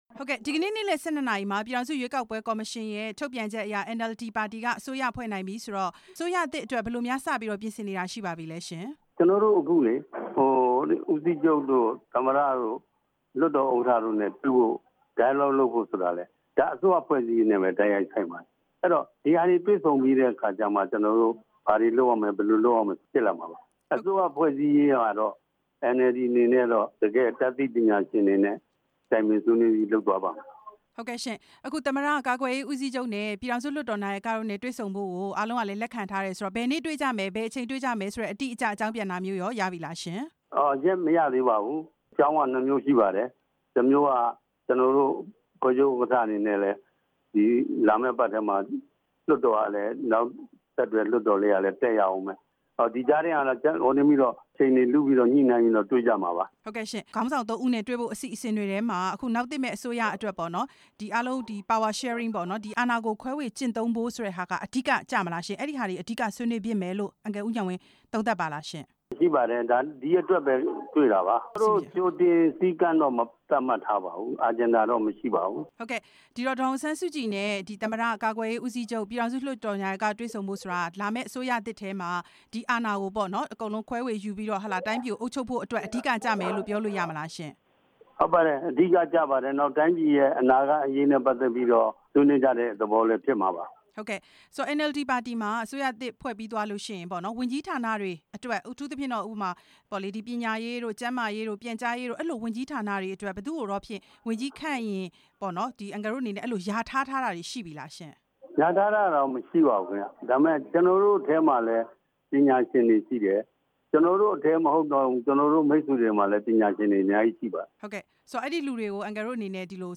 NLD ပါတီရဲ့ ဗဟိုအလုပ်အမှုဆောင်အဖွဲ့ဝင် ဦးဉာဏ်ဝင်းနဲ့ မေးမြန်းချက်